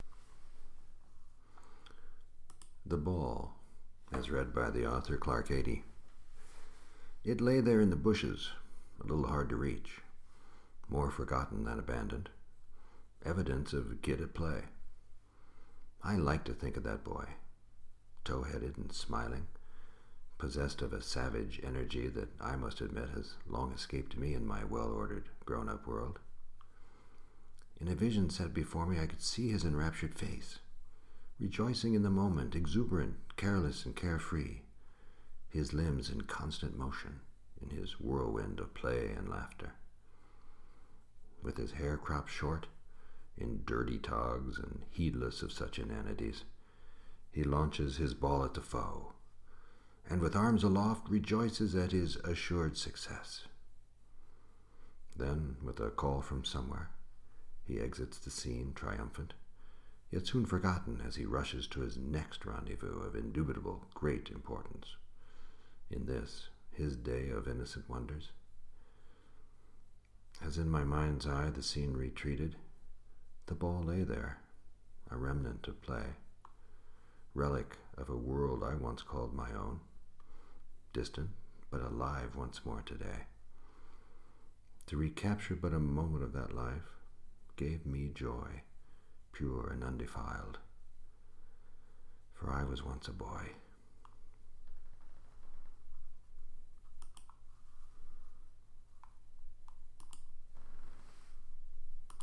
Voix - Baryton-basse